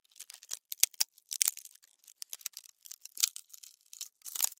Звуки термитов
Звук термита, поедающего деревянный брусок